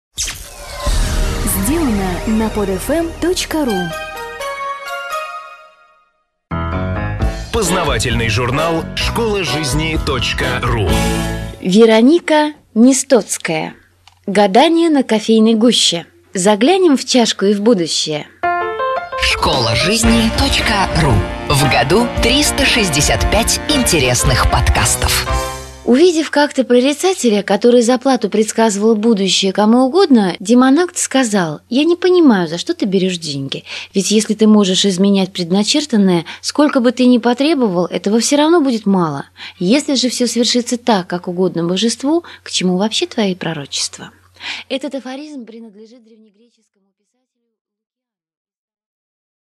Аудиокнига Гадание на кофейной гуще: заглянем в чашку и в будущее?